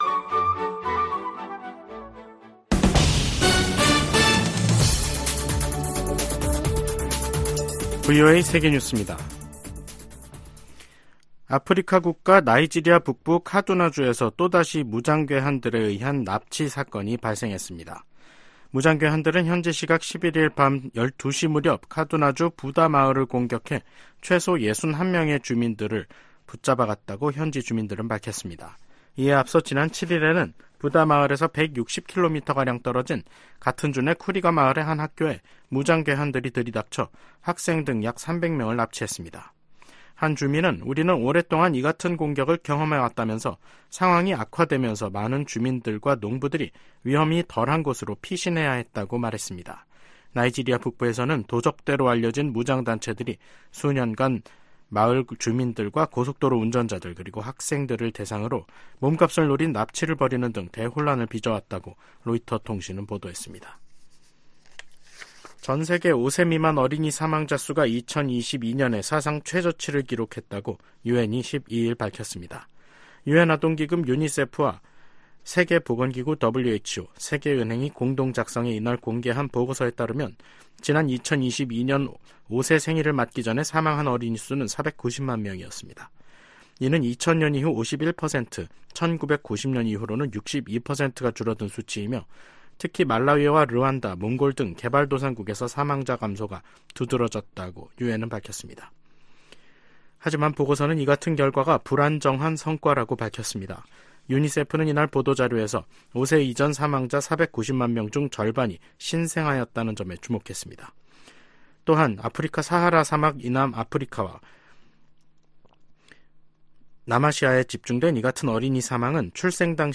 VOA 한국어 간판 뉴스 프로그램 '뉴스 투데이', 2024년 3월 13일 3부 방송입니다. 긴밀해지는 북한-러시아 관계가 김정은 국무위원장을 더 대담하게 만들 수 있다고 애브릴 헤인스 미 국가정보국장이 말했습니다.